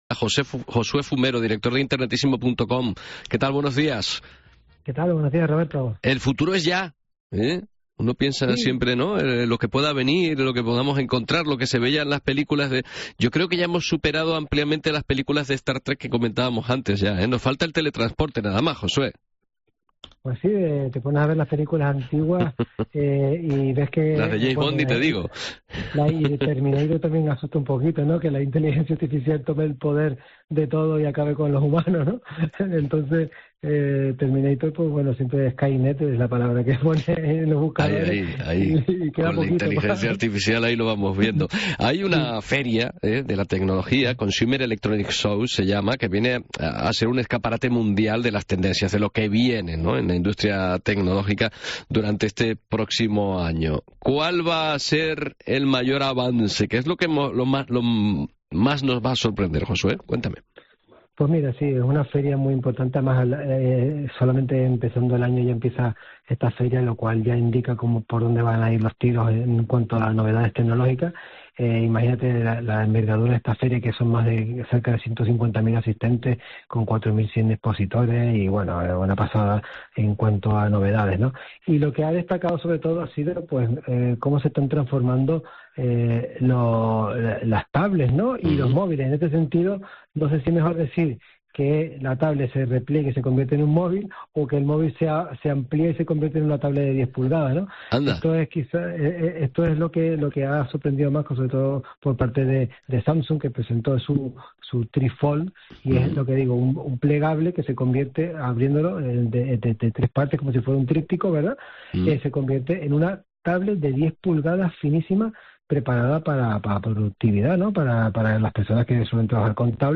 Esta semana, en el programa La mañana de COPE Canarias, hablamos sobre este congreso y las novedades que presentaron.